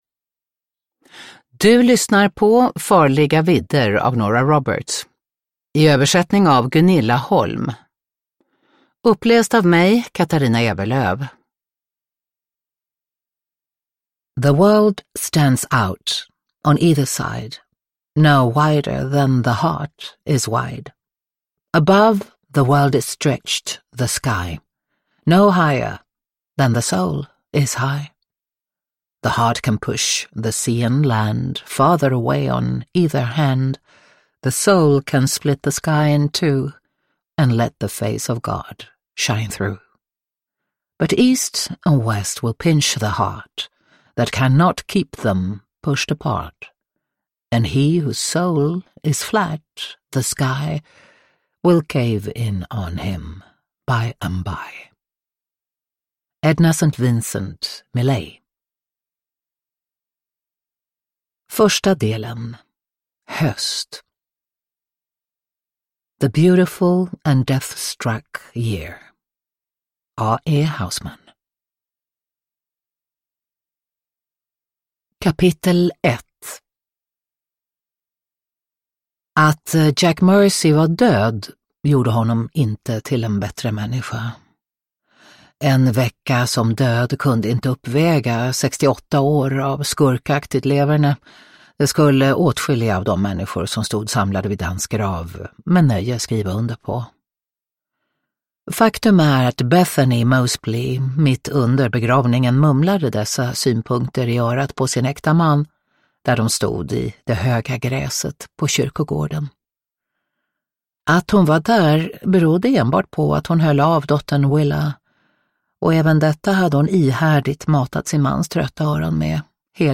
Farliga vidder – Ljudbok – Laddas ner